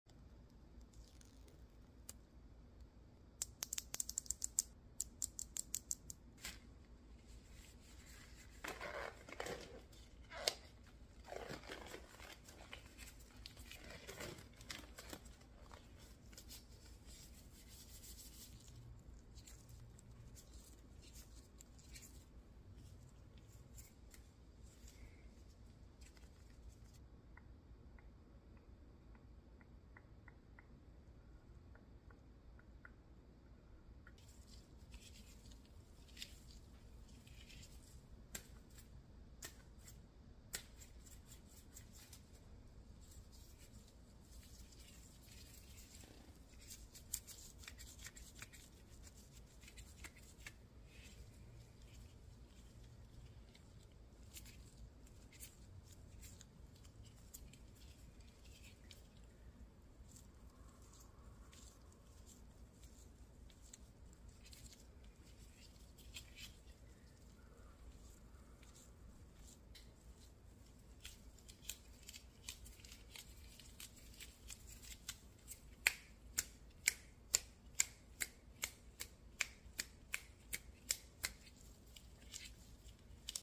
clean on tiktok oddly satisfying asmr in latex gloves screen cleaning